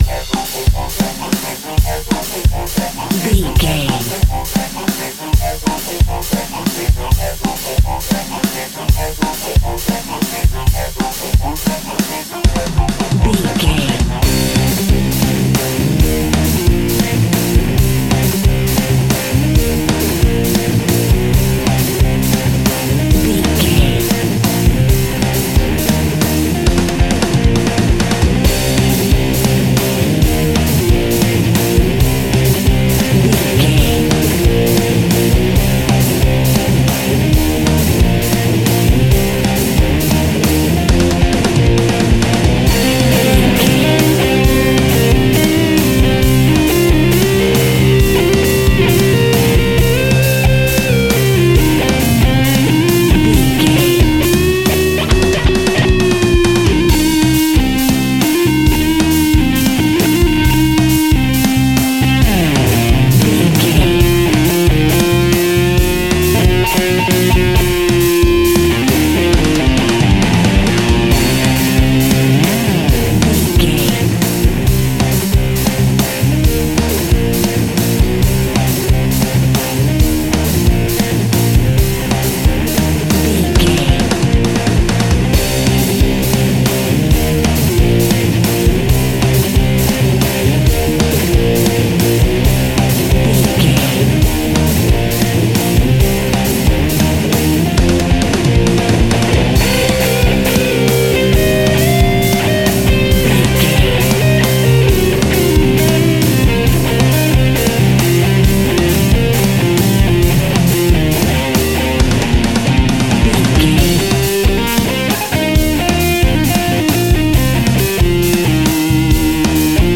Epic / Action
Aeolian/Minor
hard rock
heavy metal
blues rock
distortion
instrumentals
rock guitars
Rock Bass
heavy drums
distorted guitars
hammond organ